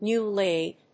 アクセントnéw‐láid